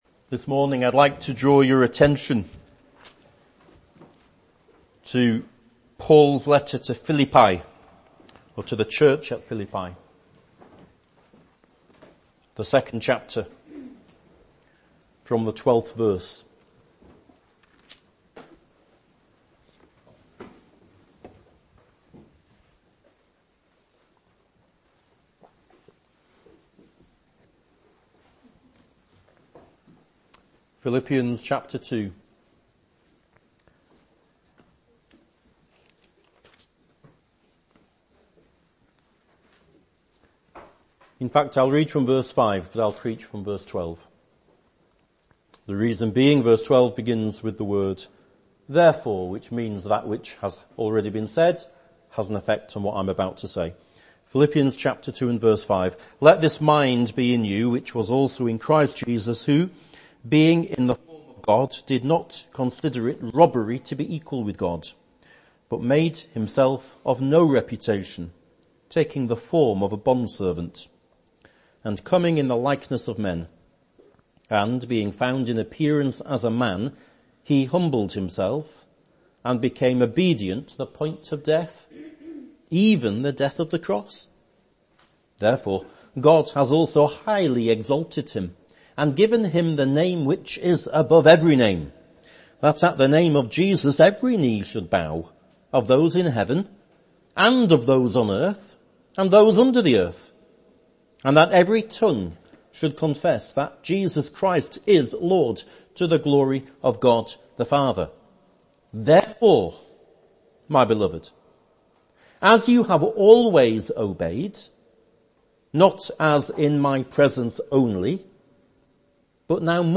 Philippians 2:12-17 | Salem Chapel, Martin Top